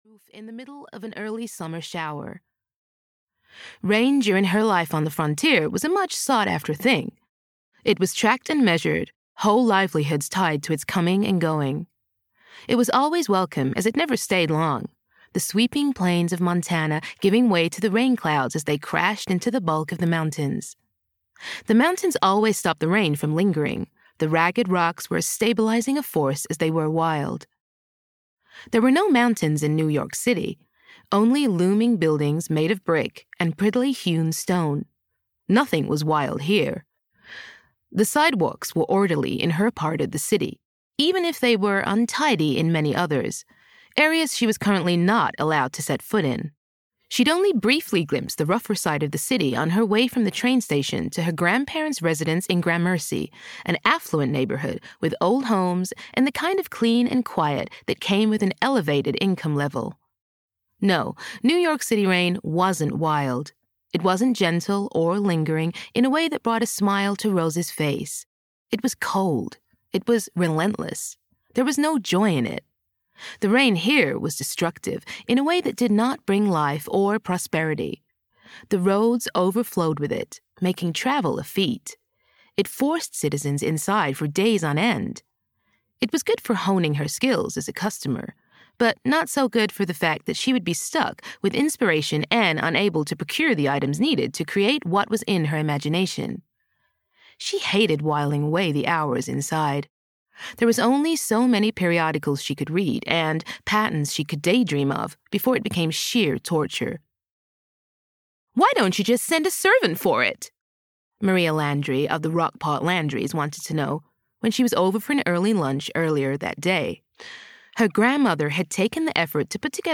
Rose and Wicked (EN) audiokniha
Ukázka z knihy